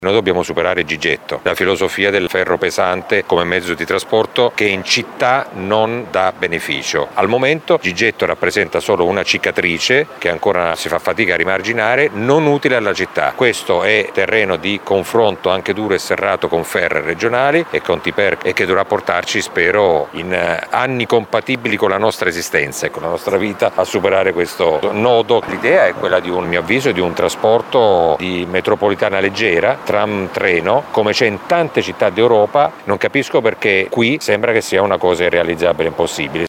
Gigetto, il treno Modena Sassuolo, che con la chiusura dei passaggi a livello paralizza il traffico in città soprattutto in via Morane e Via Fratelli Rosselli, è totalmente anacronistico e andrebbe sostituito. A dirlo il sindaco di Modena Massimo Mezzetti, intervistato durante il sopralluogo al cantiere della Stazione Piccola.
Le parole del sindaco sul treno Modena Sassuolo: